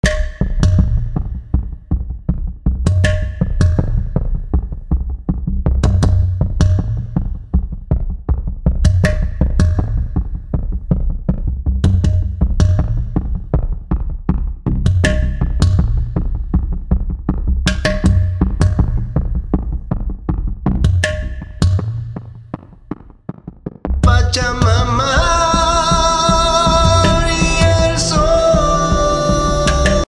Kurz vor dem Einsetzen des Gesangs, also bei Takt 8, betätige ich zudem den Schalter „Difference“, wodurch – quasi als Special Effect nur das Differenzsignal hörbar wird (Difference ist eigentlich für die Kontrolle der Einstellungen gedacht).
Und jetzt mit dem Effekt: